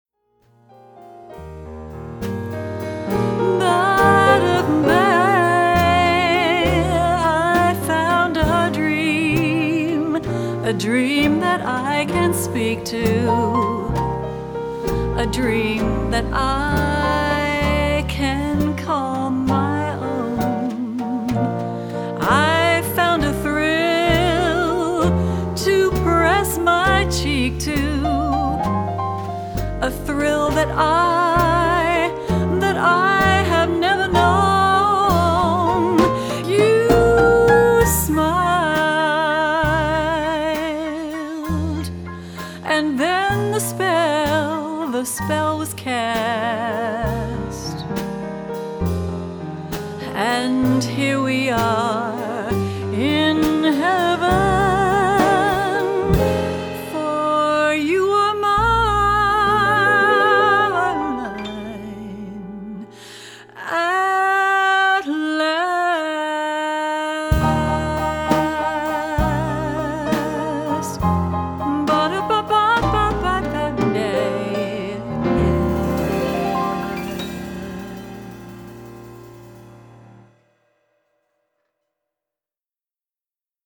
Suite 16 Recording Studio